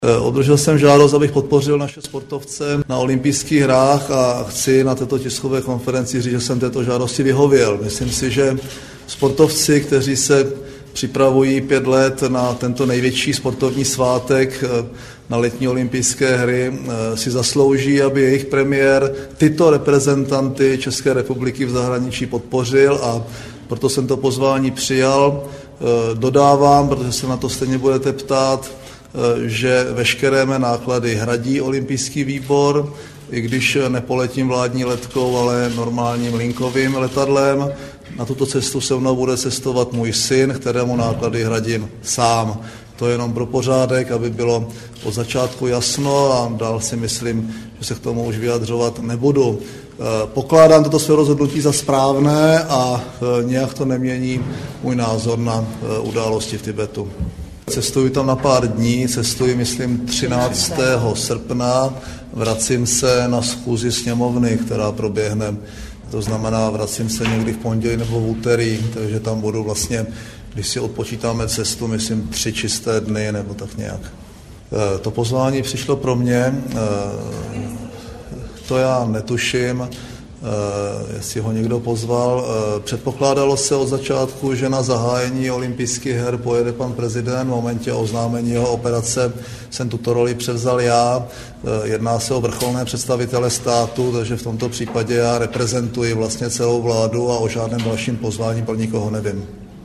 Zvukový záznam vyjádření předsedy vlády k otázkám účasti na zahájení OH v Pekingu